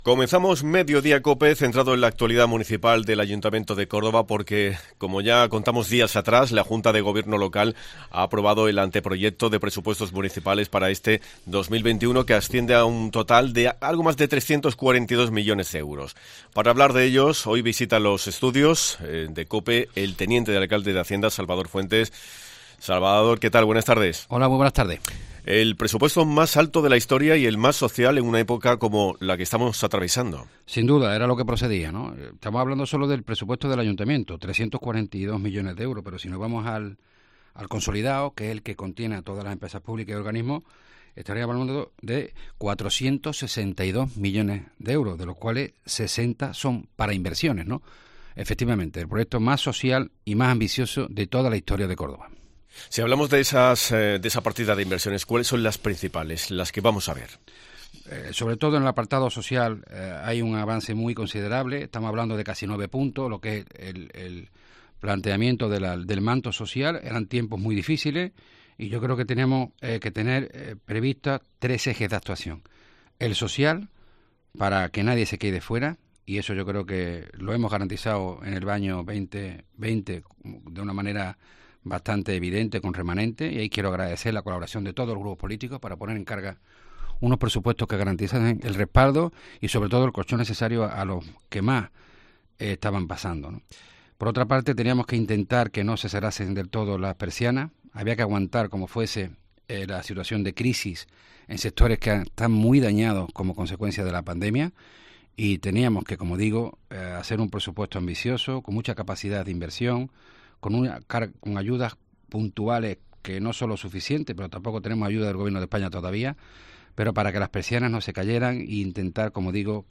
El delegado de Hacienda en el Ayuntamiento de Córdoba, Salvador Fuentes, ha analizado en COPE el antreproyecto de presupuestos 2021